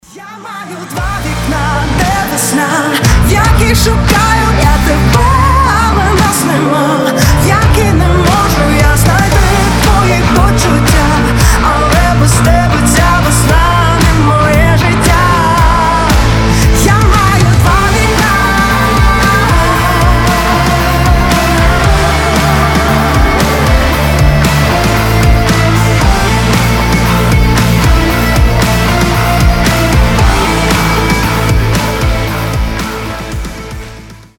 • Качество: 128, Stereo
Pop Rock
украинский рок
поп-рок